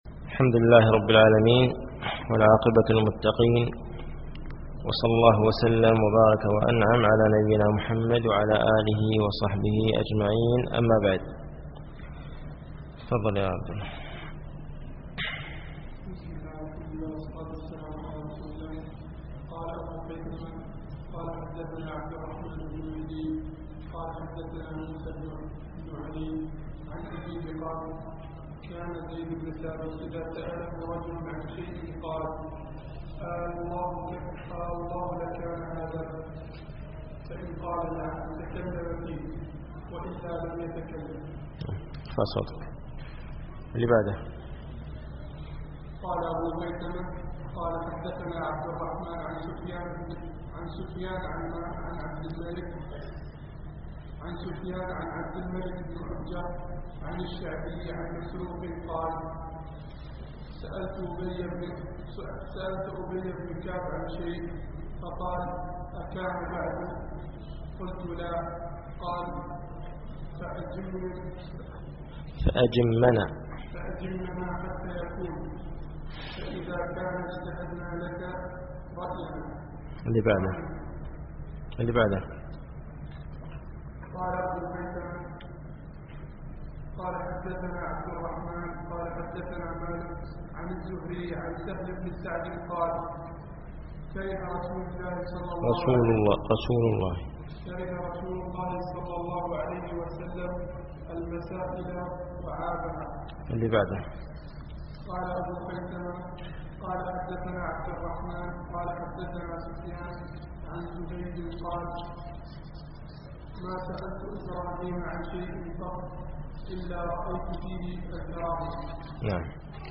التعليق على كتاب العلم لزهير بن حرب - الدرس الثاني عشر